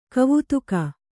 ♪ kavutuka